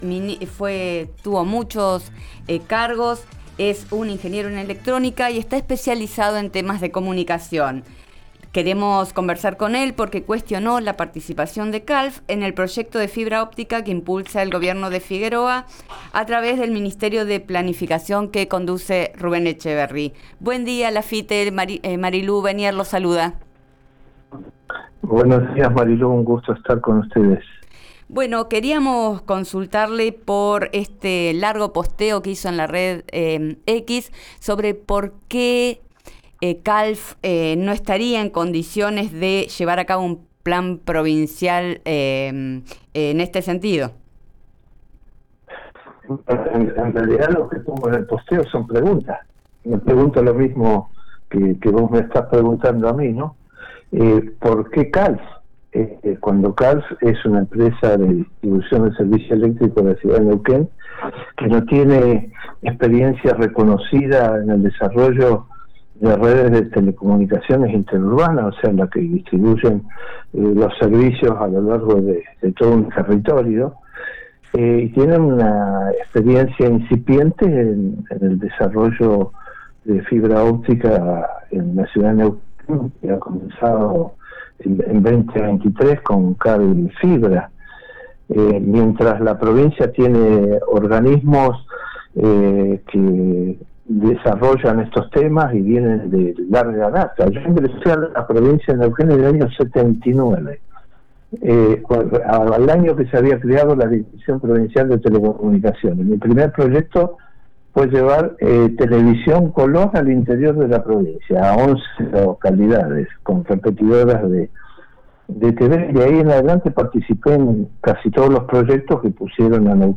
Escuchá a Rodolfo Laffitte en el aire de Vos al Aire por RÍO NEGRO RADIO: